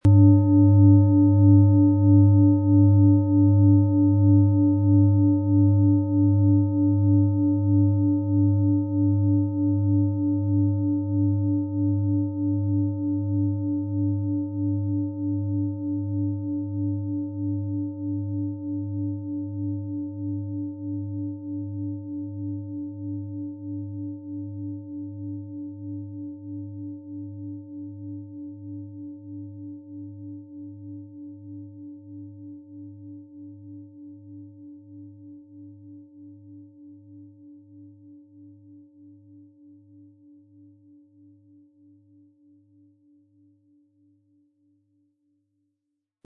Planetenton
Unter dem Artikel-Bild finden Sie den Original-Klang dieser Schale im Audio-Player - Jetzt reinhören.
Wohltuende Klänge bekommen Sie aus dieser Schale, wenn Sie sie mit dem kostenlosen Klöppel sanft anspielen.
PlanetentonMars
MaterialBronze